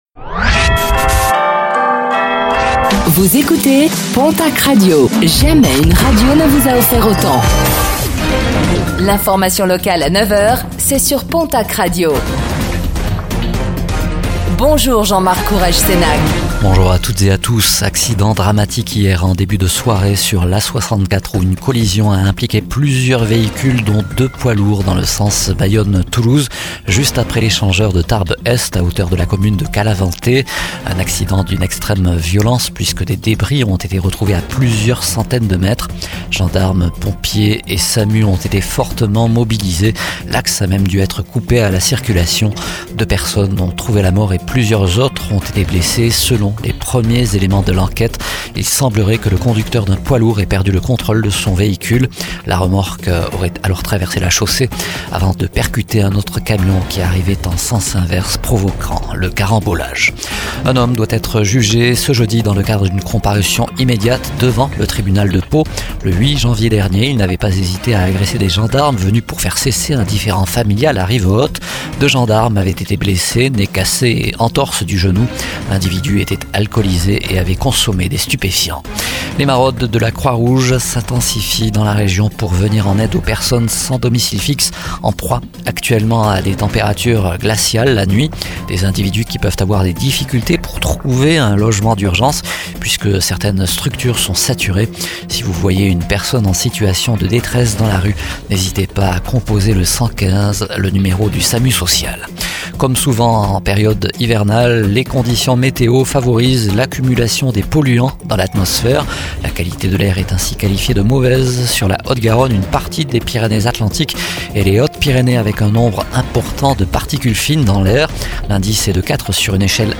Infos | Jeudi 16 janvier 2025